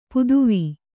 ♪ puduvī